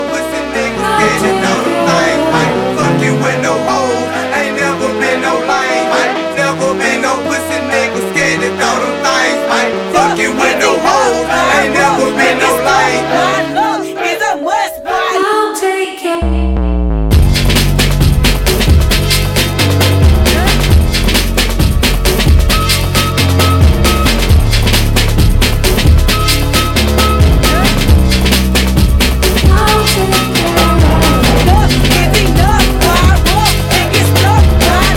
Rap Hip-Hop Rap
Жанр: Хип-Хоп / Рэп